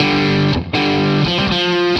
Index of /musicradar/80s-heat-samples/120bpm
AM_HeroGuitar_120-G02.wav